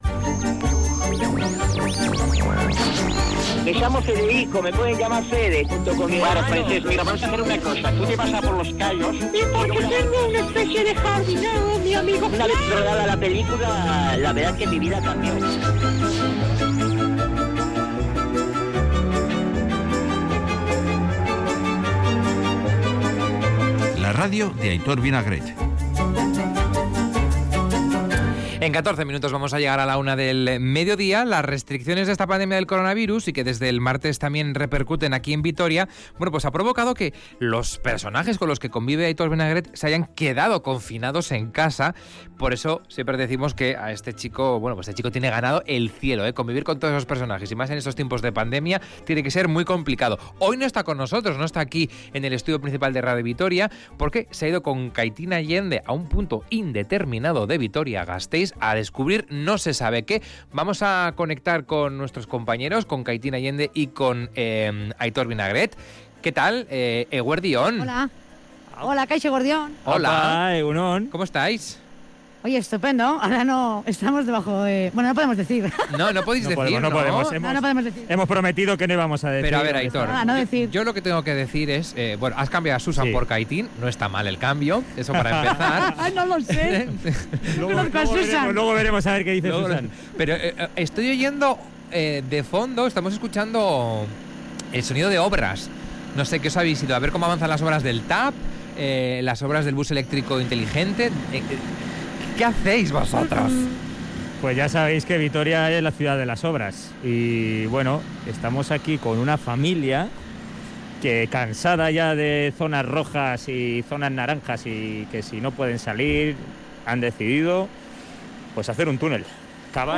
La unidad móvil es testigo del túnel que se está excavando de Gasteiz a Cantabria
Mucho humor.